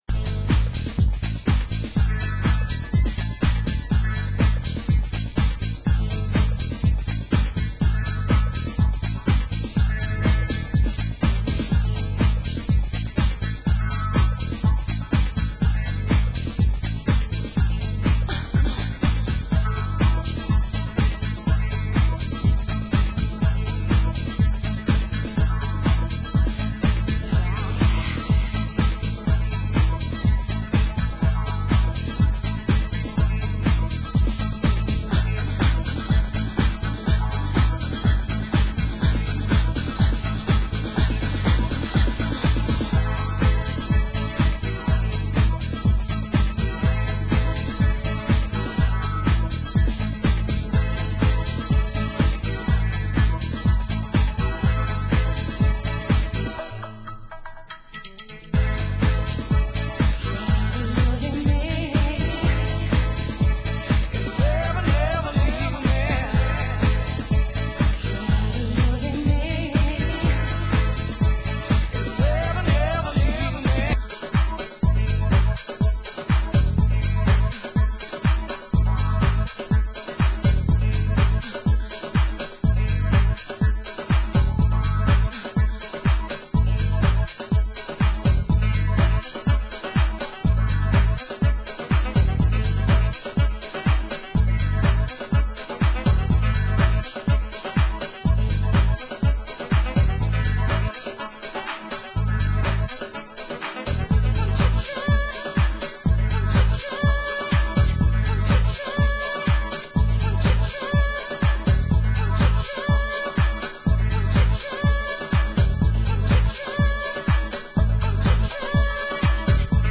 Format: Vinyl 12 Inch
Genre: US House